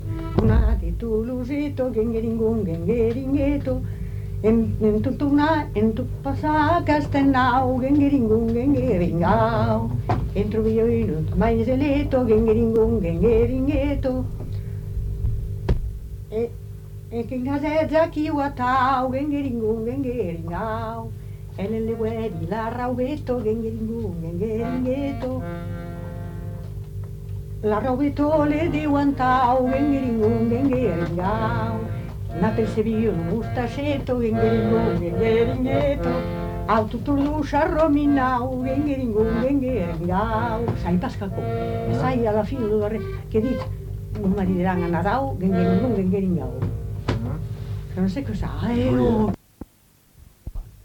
Lieu : Polastron
Genre : chant
Effectif : 1
Type de voix : voix de femme
Production du son : chanté
Description de l'item : fragment ; 4 c. ; refr.